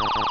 LASER3.WAV